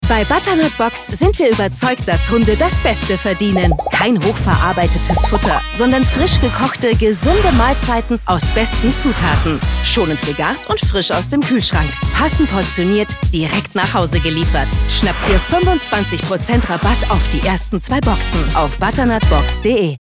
markant, sehr variabel, hell, fein, zart
Jung (18-30)
Commercial (Werbung)